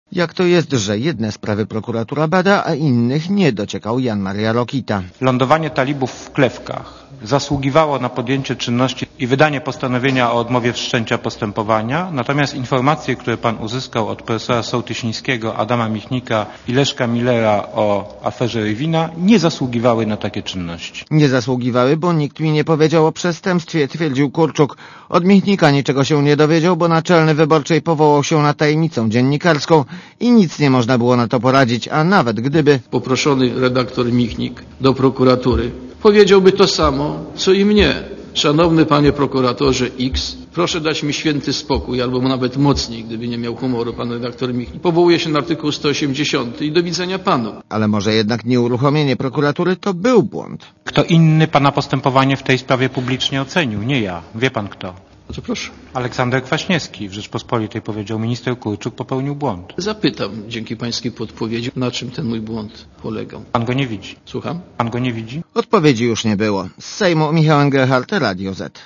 (PAP) Prokuratura nie reagowała w sprawie Rywina, bo nikt mi nie powiedział o przestępstwie - mówił przed sejmową komisją śledczą minister sprawiedliwości i prokurator generalny, Grzegorz Kurczuk.